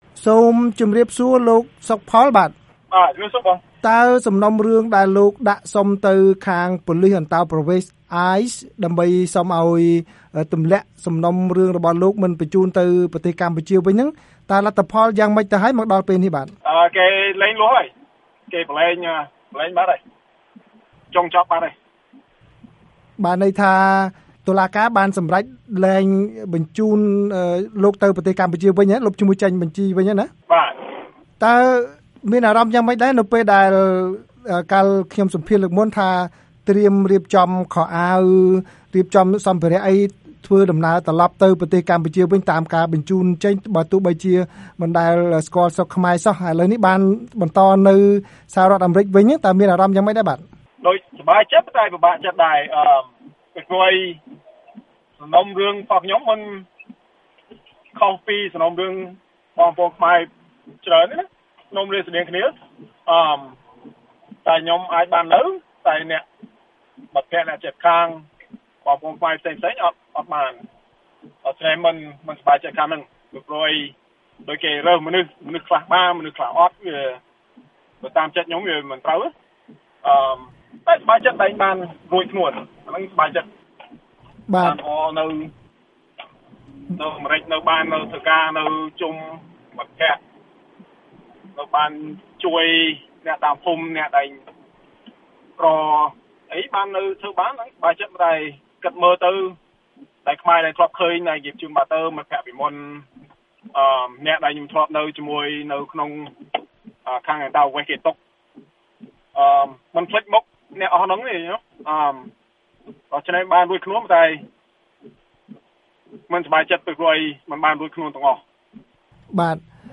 បទសម្ភាសន៍ VOA៖ អតីតអ្នកទោសខ្មែររួចផុតពីការបណ្តេញចេញដោយសារការងារសហគមន៍